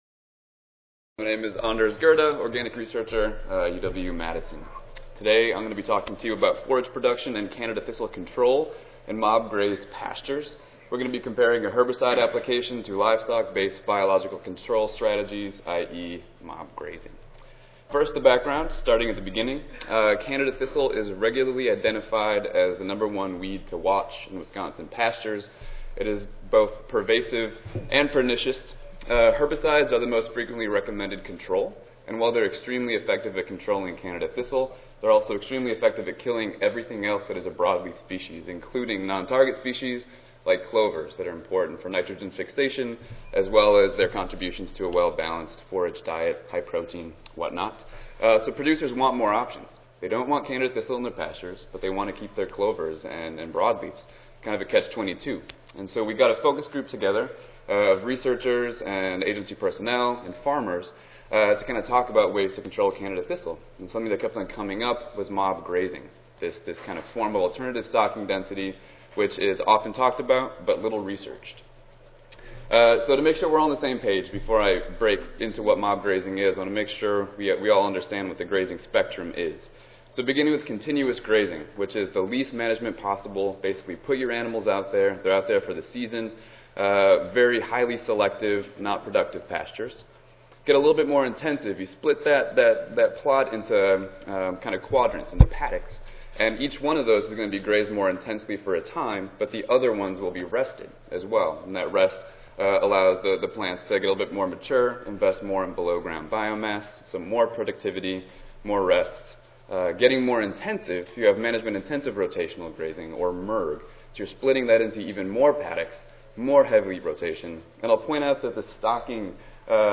USDA-ARS Audio File Recorded Presentation